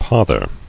[poth·er]